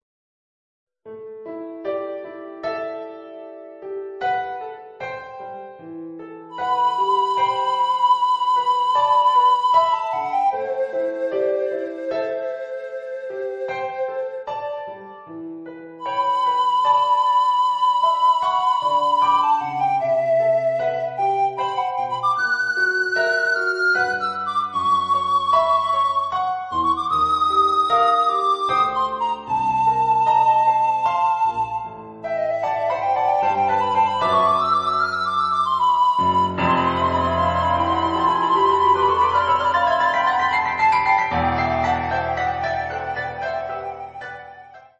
treble recorder, piano
(Audio generated by Sibelius/NotePerformer)